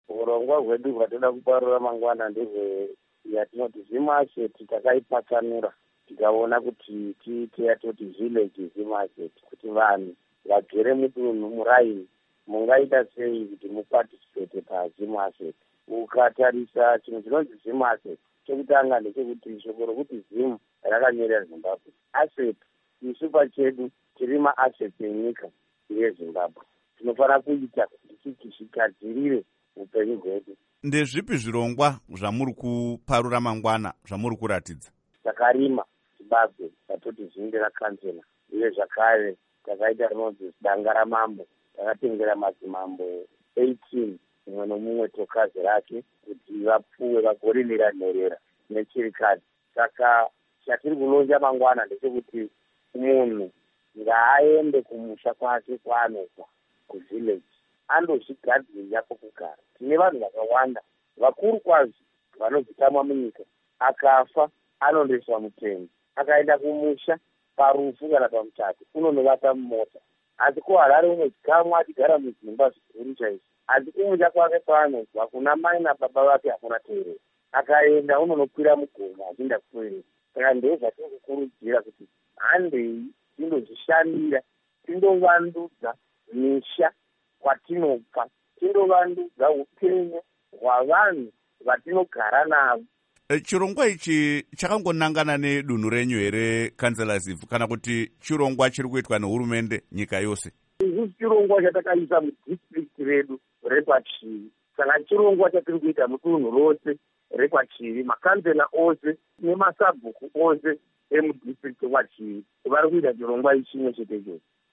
Hurukuro naVaKiller Zivhu